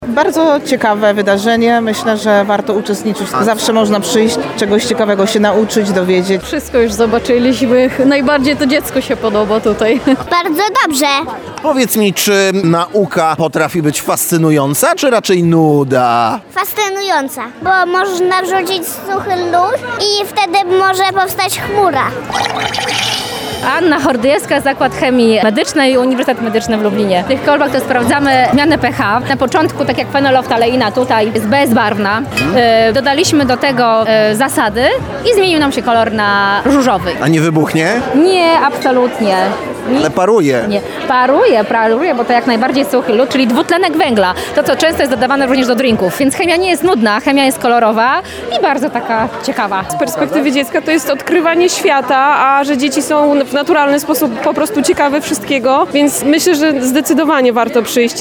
Z perspektywy dziecka to jest odkrywanie świata – mówią uczestnicy wydarzenia.